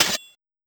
sword_hit.wav